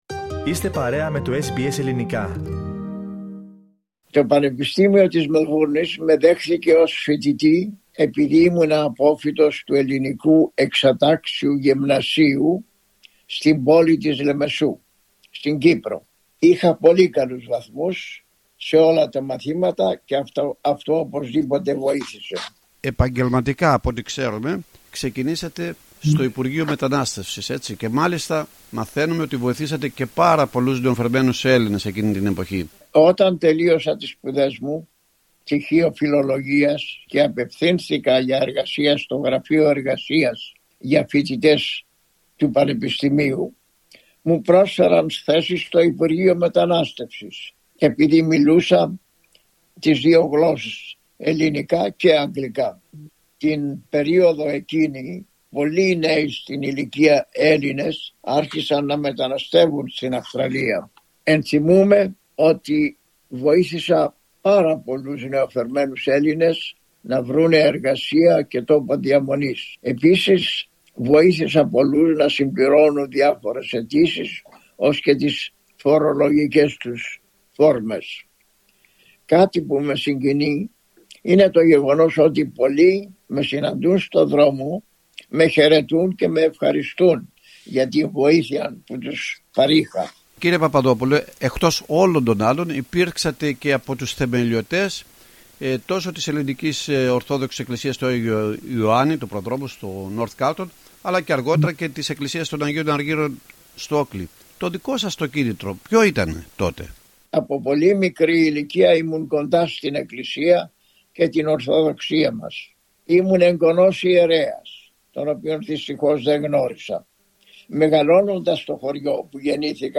Στην συνέντευξη που παραχώρησε στο Πρόγραμμά μας, SBS Greek, μας εξιστορεί τη διαδρομή του με δικά του λόγια — από τα πρώτα του βήματα στη Μελβούρνη, μέχρι τις μνήμες, τις αξίες και τα οράματά του για την ελληνική παροικία της Αυστραλίας.